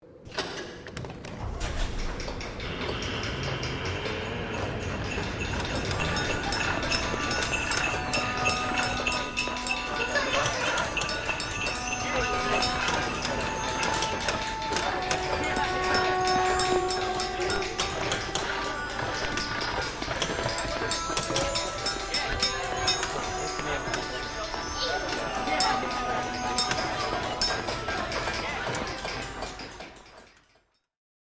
Toy Makers Workshop is a free ambient sound effect available for download in MP3 format.